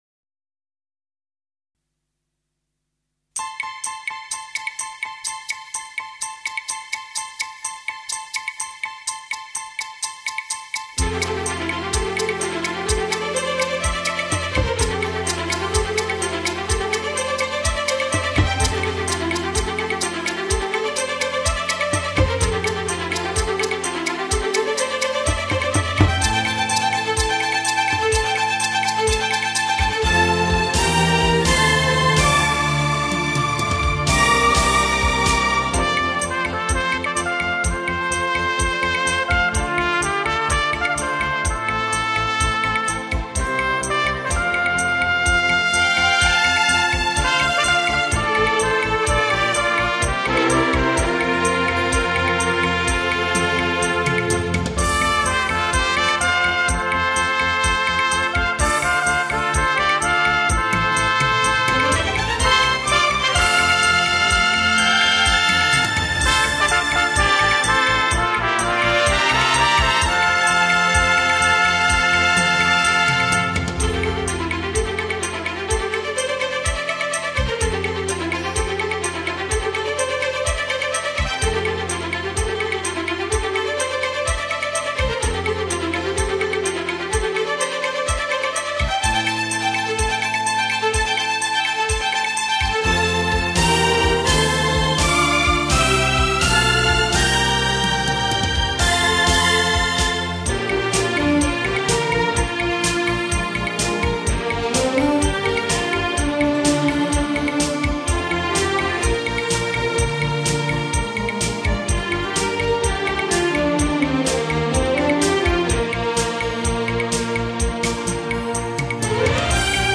演唱歌手： 纯音乐
蒙古高原上的音乐风。